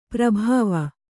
♪ prabhāva